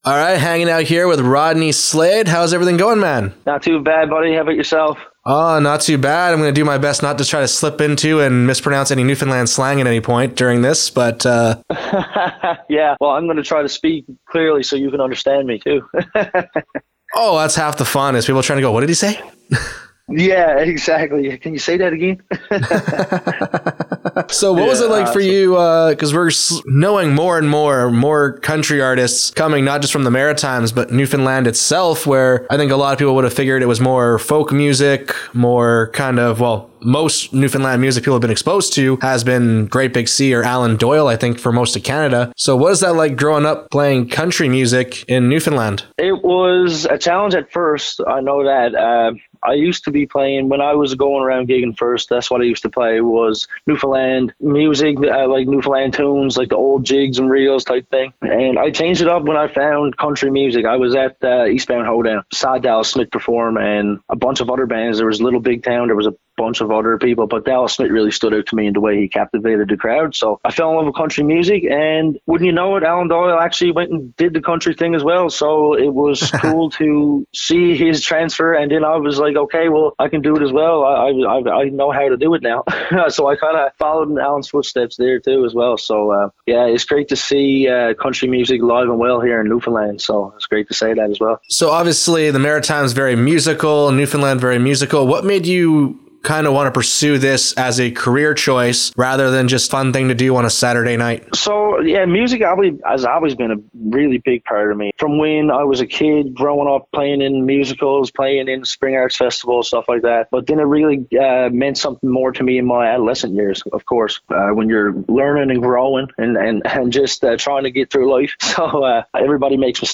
Rising Stars Headliner Interview